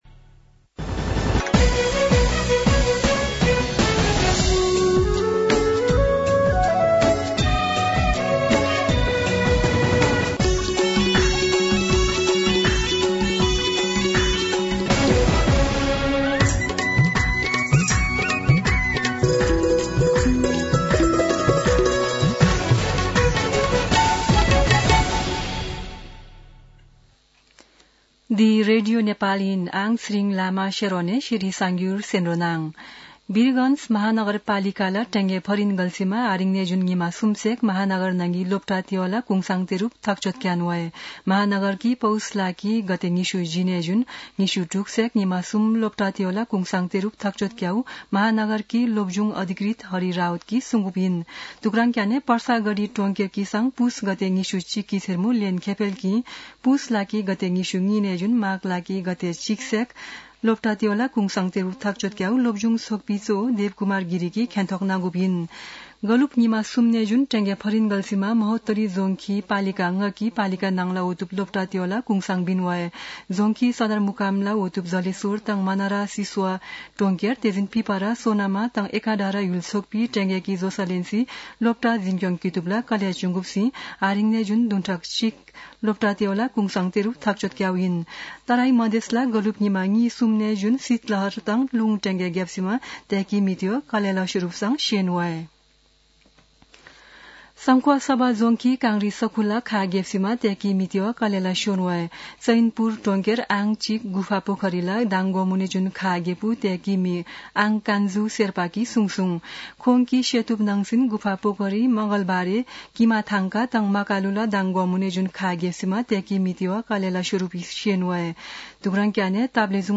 शेर्पा भाषाको समाचार : २५ पुष , २०८१
Sherpa-news-2.mp3